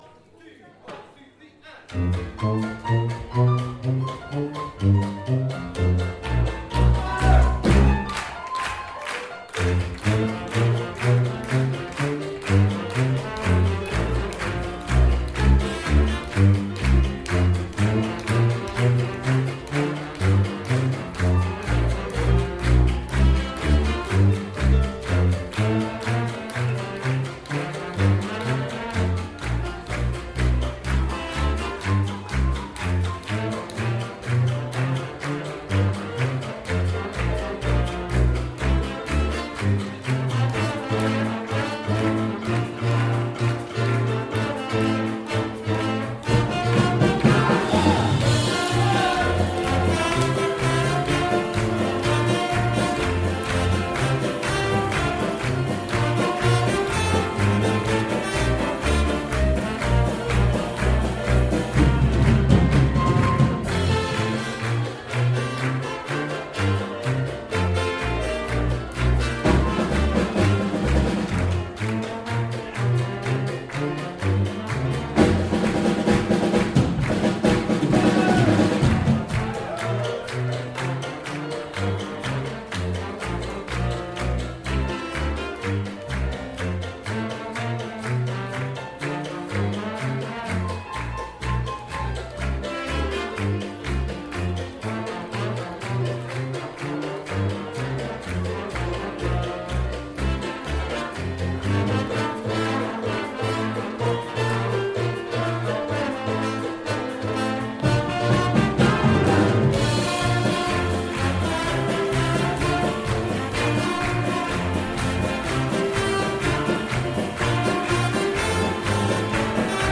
Year 10 Trad Band March 2018